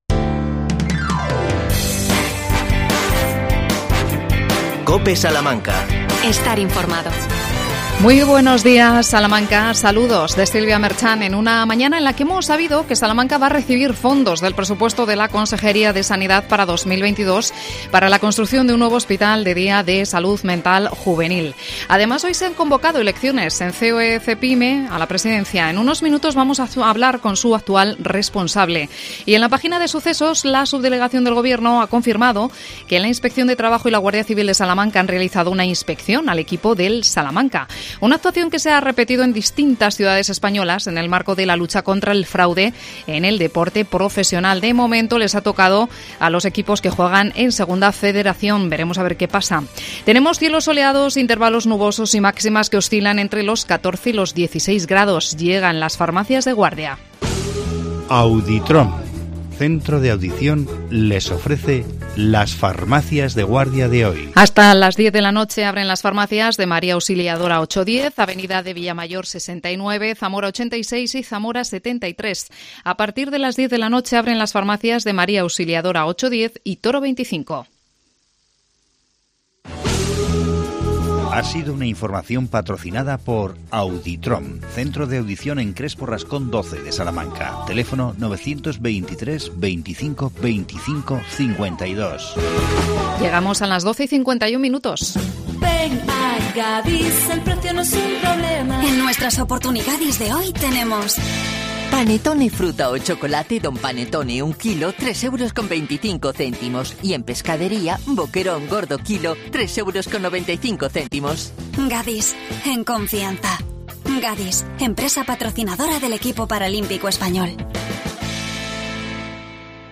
Entrevista al presidente